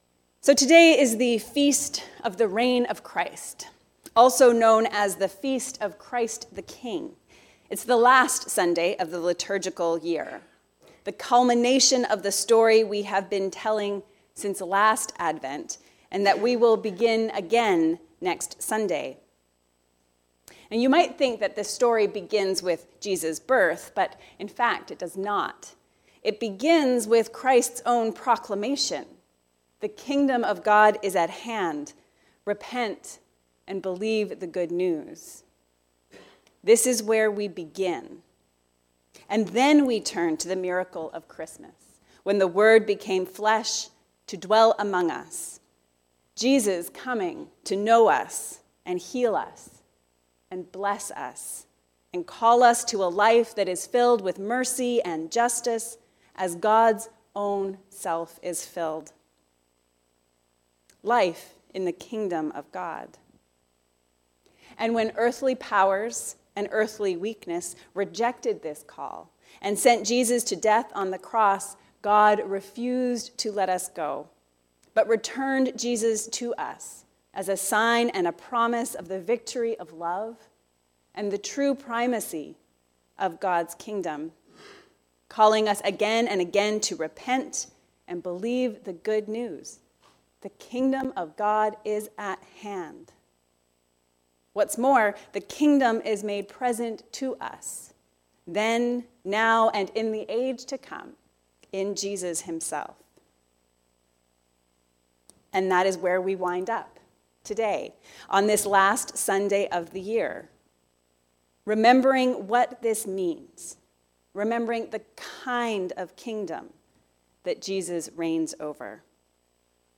The choice is yours. Choose to be a sheep. A sermon on Matthew 25:31-46